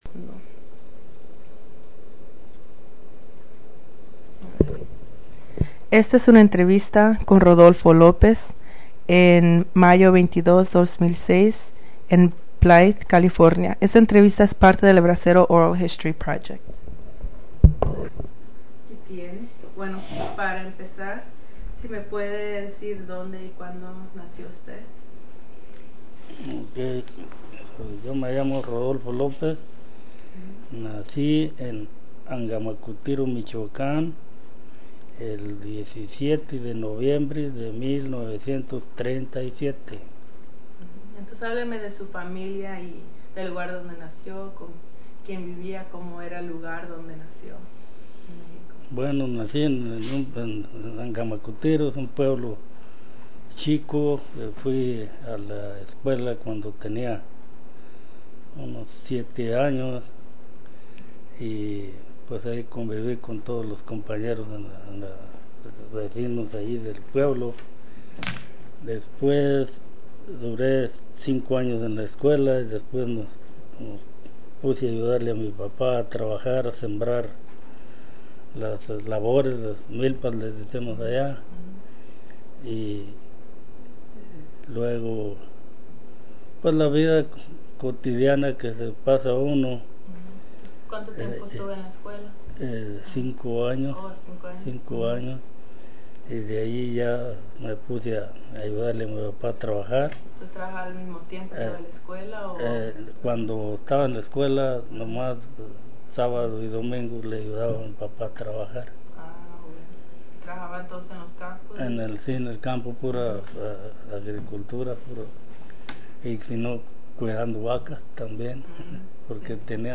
Summary of Interview
Location Blythe, CA Original Format Mini disc